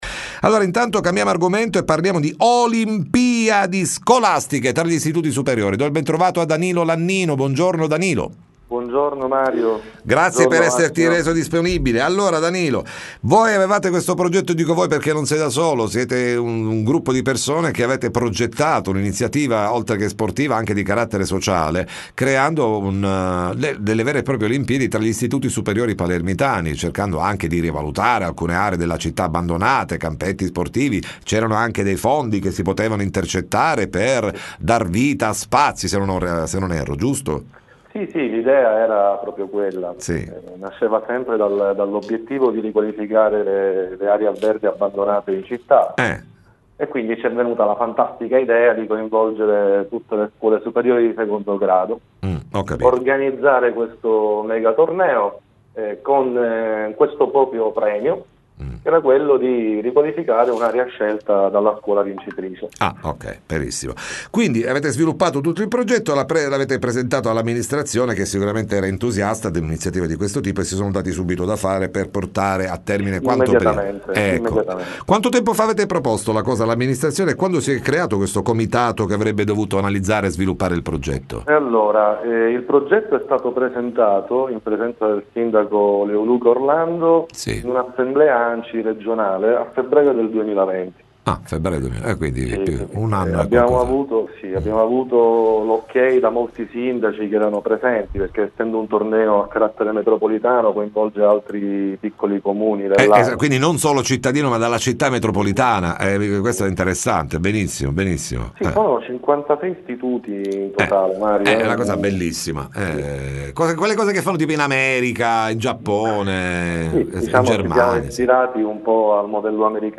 Olimpiadi scolastiche, riascolta l’intervista